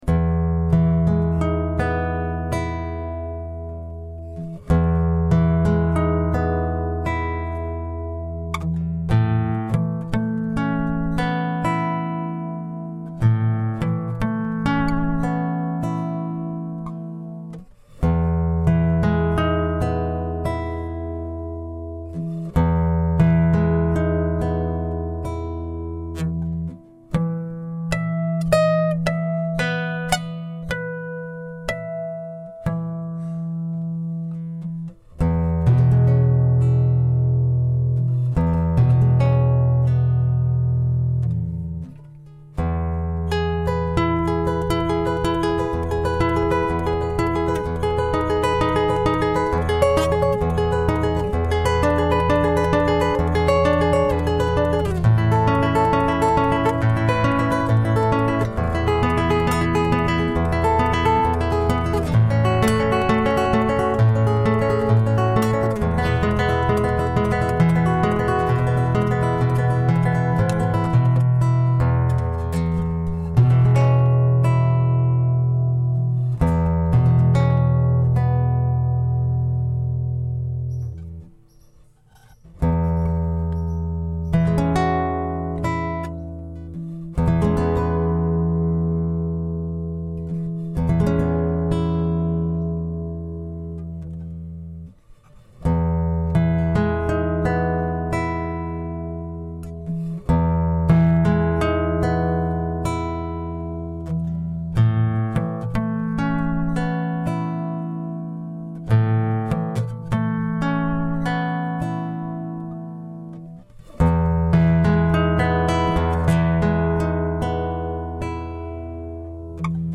- Guitare Classique
Je suis désolé pour le son qui sature.
J'aime beaucoup comme tu alternes, entre les parties très douces, jouées délicatement et les autres avec plus d'énergie, de puissance !